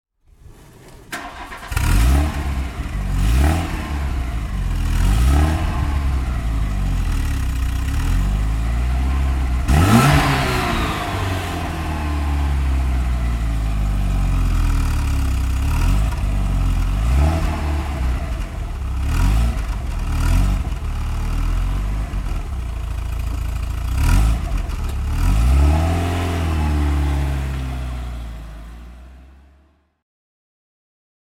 VW Golf II GTI (1989) - Starten und Leerlauf
VW_Golf_GTI_1989.mp3